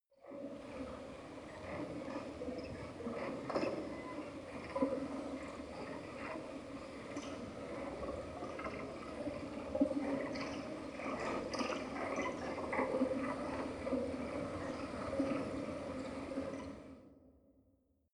Gemafreie Sounds: Höhle